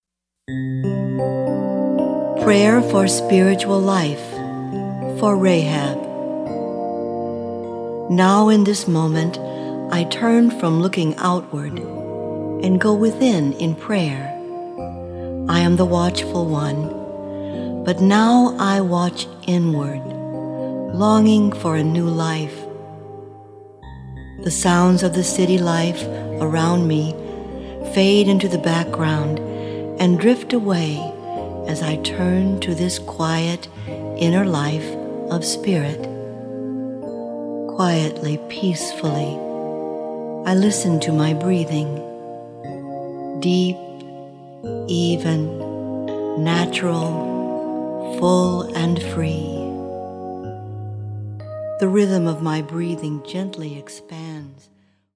This double CD includes a 3 minute meditation and a song for each of the 12 powers interpreted through the Divine Feminine.